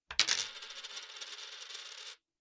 Efectos de sonido
moneda_wwedsds.mp3